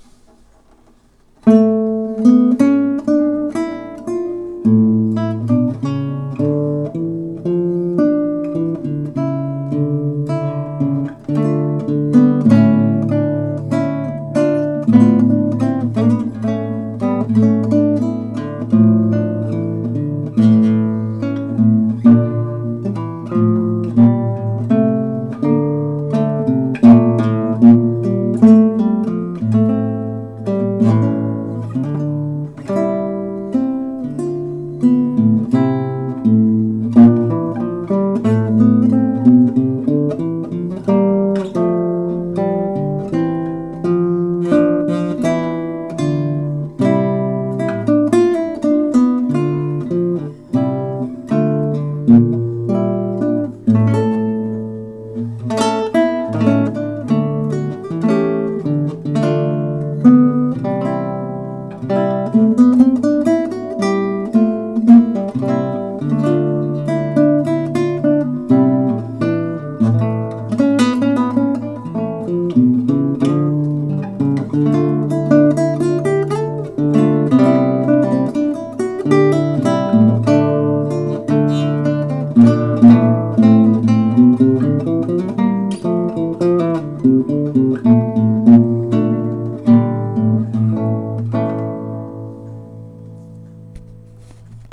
Live Classical Guitar performances